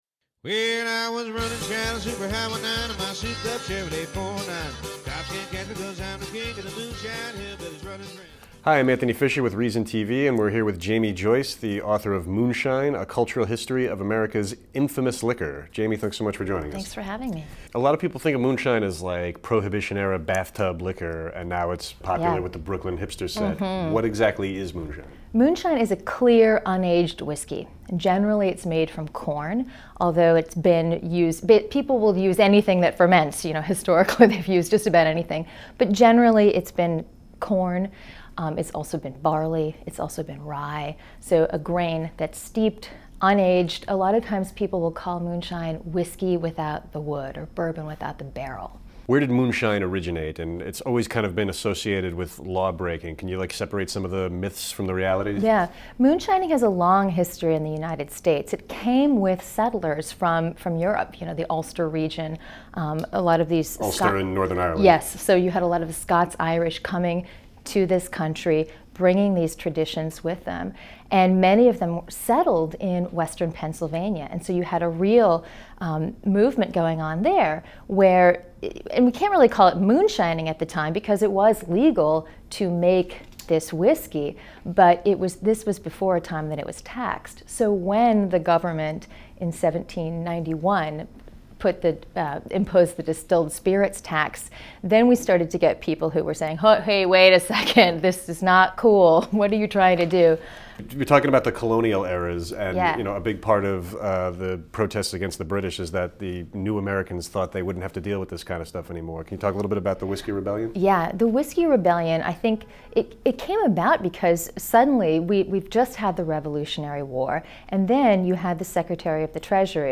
Q&A w